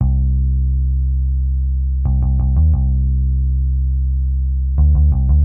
描述：重低音
Tag: 88 bpm Weird Loops Bass Guitar Loops 939.75 KB wav Key : Unknown Reason